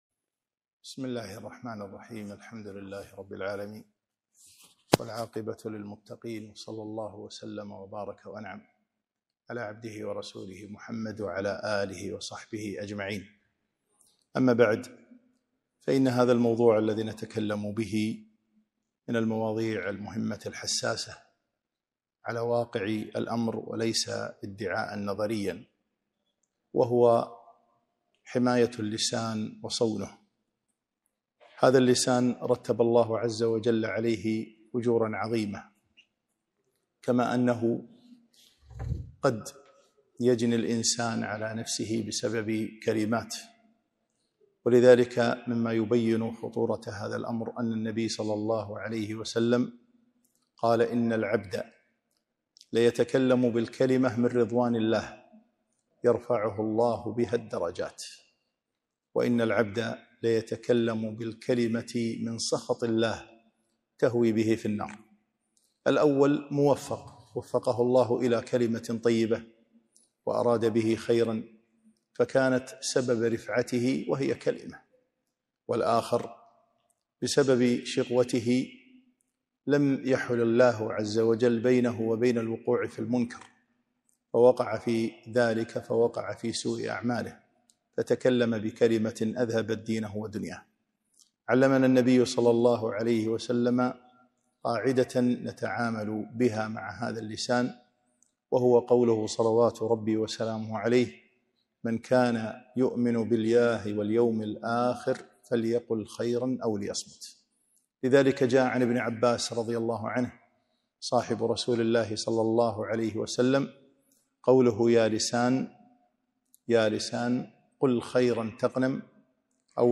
كلمة - صون اللسان